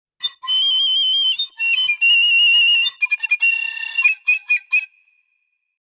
When it came to reproducing this song on the piccolo, I actually played trills for the slower alternating sounds, but for the buzzy trill towards the end, (presumably the ‘distinctive trill’ everyone is referring to), I opted for a flutter-tongue technique (see future post).
piccolo-wren.mp3